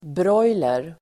Ladda ner uttalet
broiler.mp3